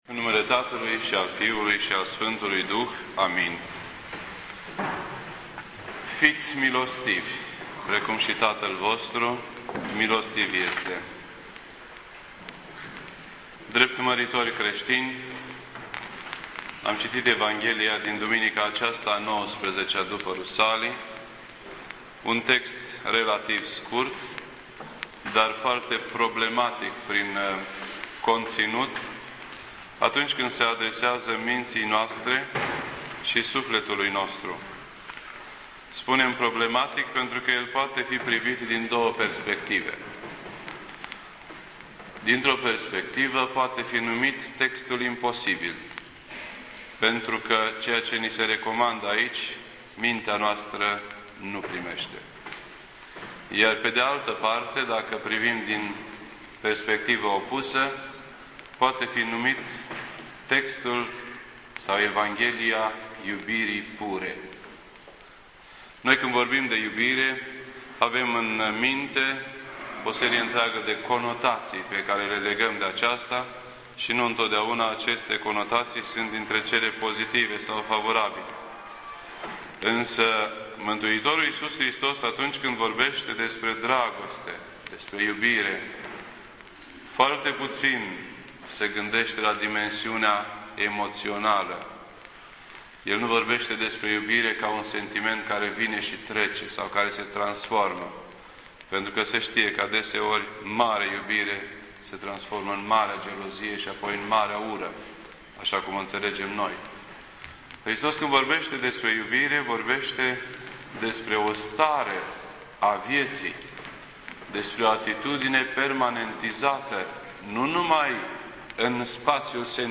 This entry was posted on Sunday, October 4th, 2009 at 6:34 PM and is filed under Predici ortodoxe in format audio.